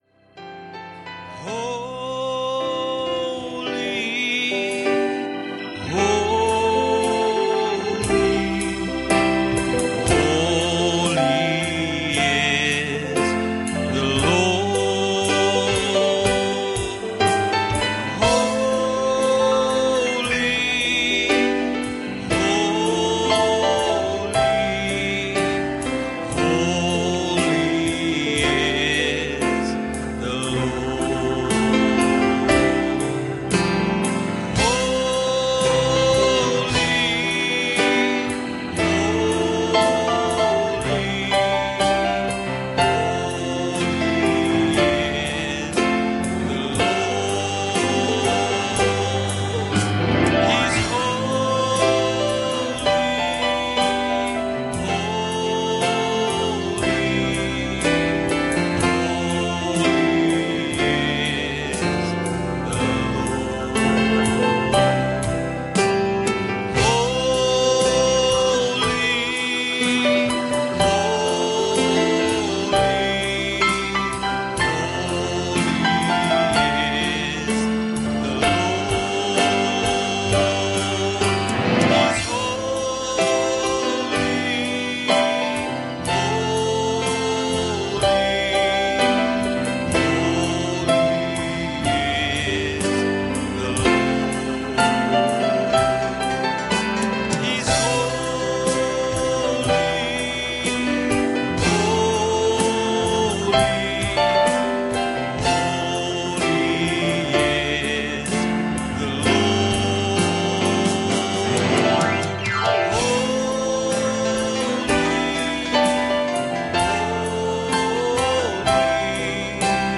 Passage: 1 Chronicles 21:17 Service Type: Sunday Morning